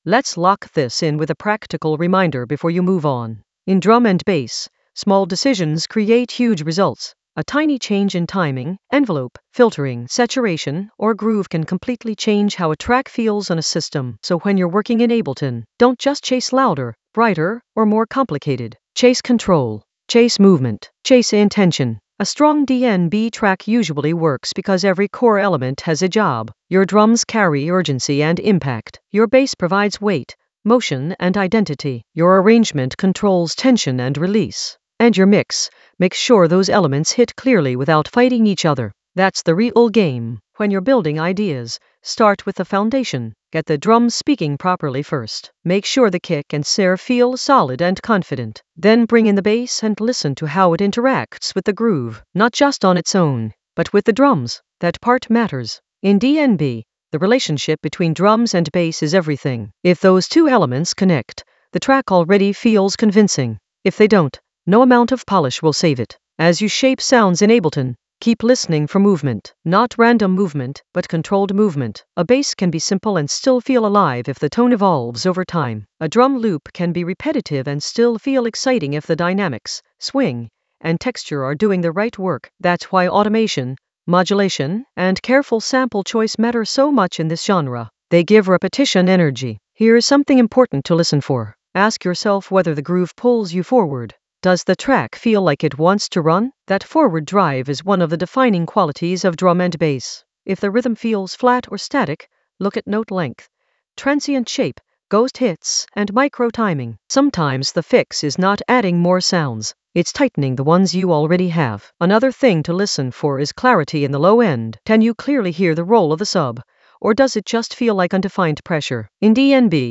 An AI-generated intermediate Ableton lesson focused on Colin Dale method: craft a hypnotic low-end flow in Ableton Live 12 for deep drum and bass moods in the Groove area of drum and bass production.
Narrated lesson audio
The voice track includes the tutorial plus extra teacher commentary.